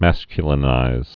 (măskyə-lə-nīz)